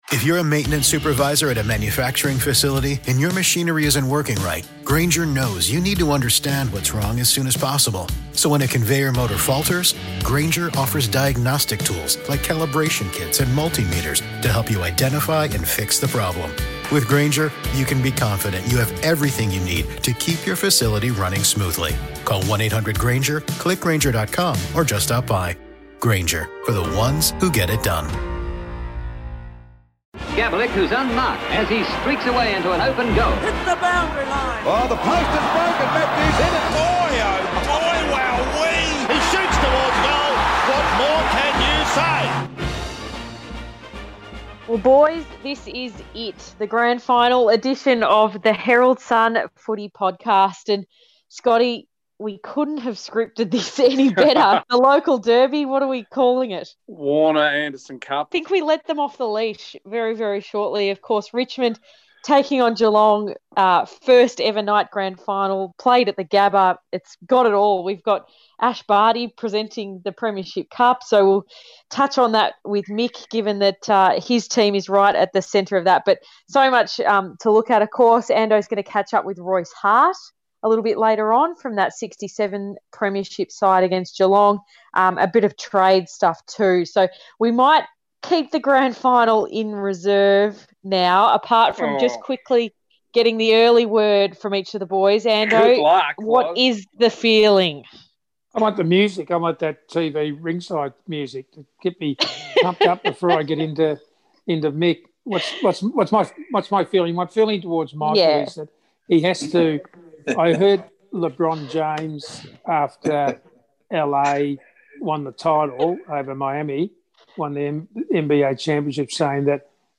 Will the Tigers claim their third flag in four years or will Geelong send champion Gary Ablett out in style? Our experts give their Grand Final predictions and debate Jeremy Cameron's likely move to Geelong, the North Melbourne coaching situation and the virtual Brownlow plus an exclusive interview with Richmond legend Royce Hart